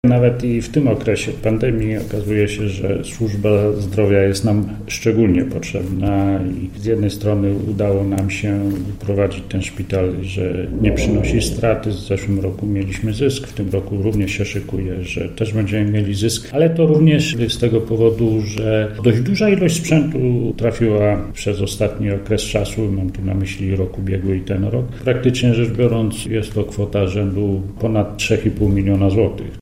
’- To ważne urządzenia w dobie rehabilitacji pocovidowej – mówi Krzysztof Romankiewicz, starosta zielonogórski.